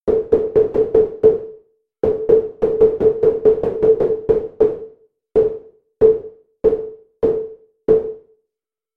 Envelope Attack Decay + Filtro Passa Banda Pure Data
描述：纯数据+带通滤波器中使用ead模块的例子
Tag: 敲击 声音 FX